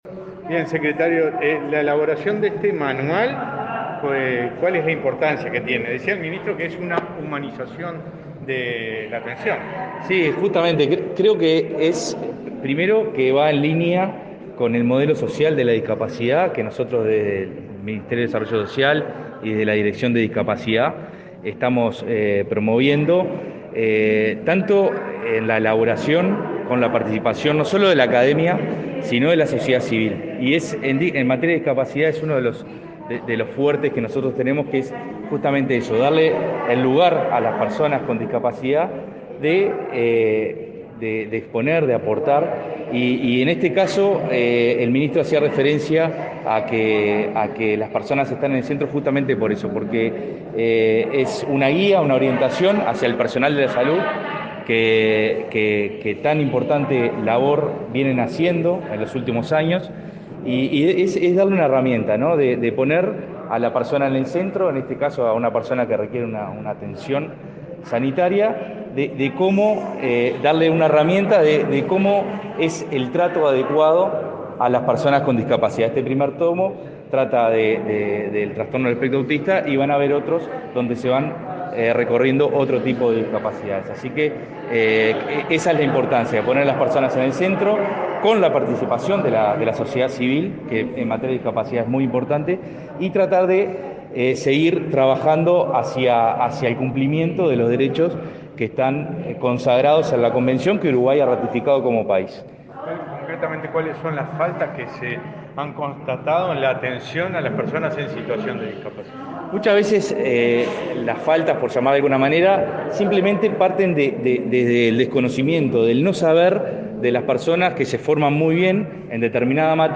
Declaraciones de prensa del secretario nacional de Cuidados y Discapacidad del Mides
El secretario nacional de Cuidados y Discapacidad del Mides, Nicolás Scarela, dialogó con la prensa sobre el evento realizado este miércoles 15 en el